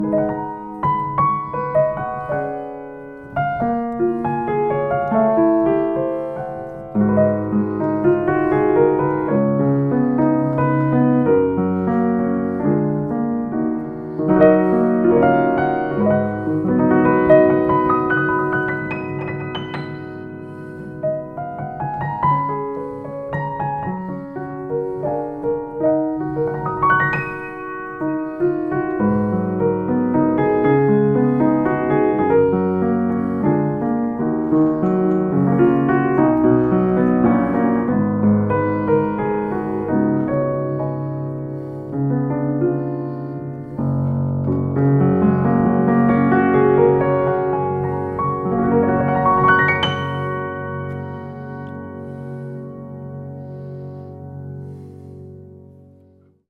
Yamaha-P2.mp3